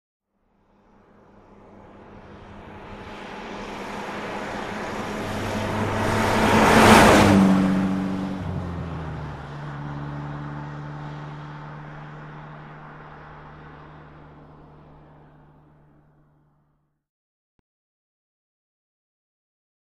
Aston Martin; By, Fast; Steady Engine, Fast By With Tire Whine, Gears Shift After By. Medium Perspective. Sports Car, Auto.